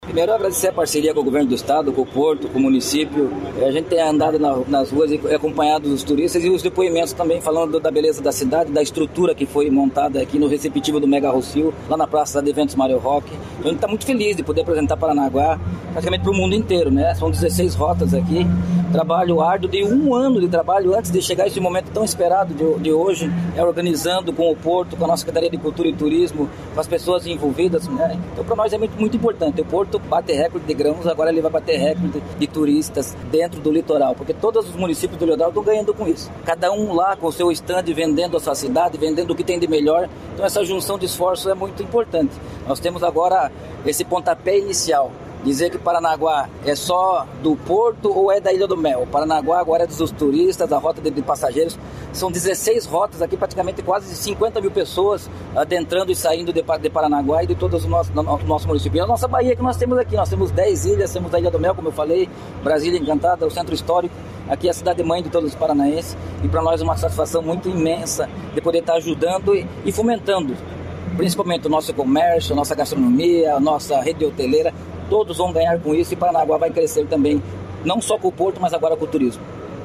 Sonora do prefeito de Paranaguá, Marcelo Roque, sobre o Porto de Paranaguá receber os primeiros navios de cruzeiros da temporada